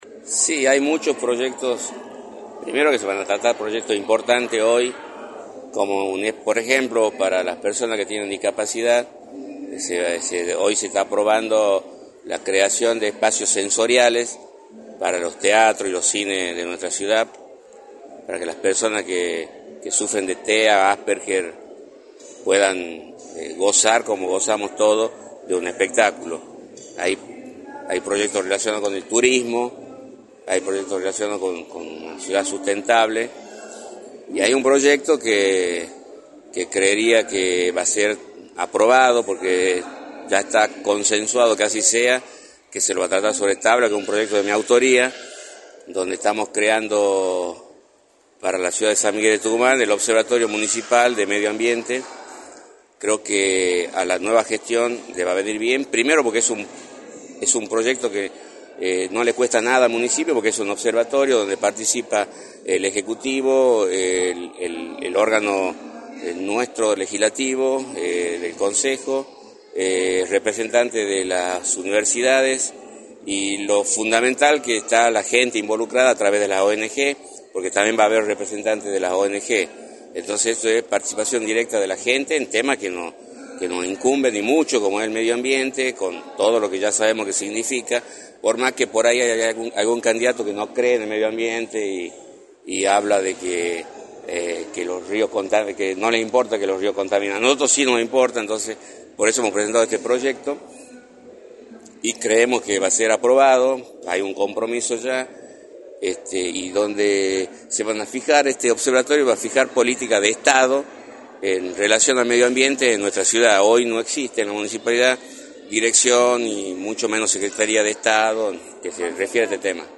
“Hoy se está probando la creación de espacios sensoriales para los teatros y los cines de nuestra ciudad, para que las personas que sufren de TEA y Asperger, puedan gozar, como gozamos todos, de un espectáculo, hay proyectos relacionados con el turismo, hay proyectos relacionados con la ciudad sustentable, y hay un proyecto que creería que va a ser aprobado, que es un proyecto de mi autoría, dónde estamos creando para la ciudad de San Miguel de Tucumán, el Observatorio Municipal de Medio Ambiente” señaló Lucho Argañaraz en entrevista para “La Mañana del Plata”, por la 93.9.